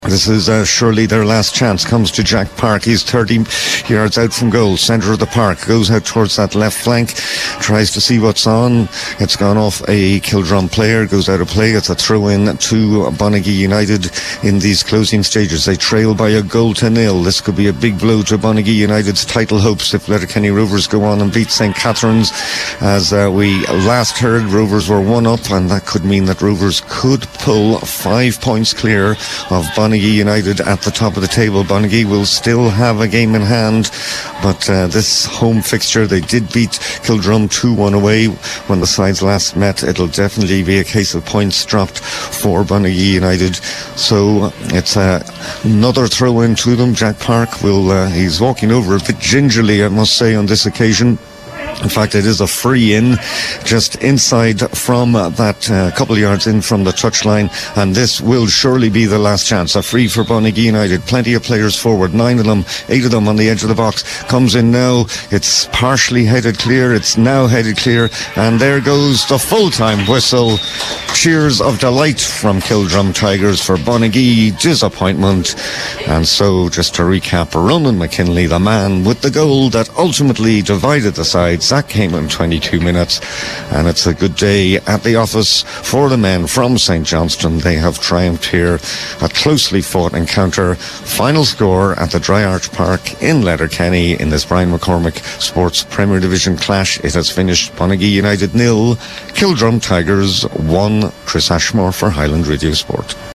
was live as the home side pressed an equaliser approaching full time at The Dry Arch…